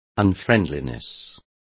Complete with pronunciation of the translation of unfriendliness.